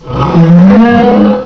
cry_not_bouffalant.aif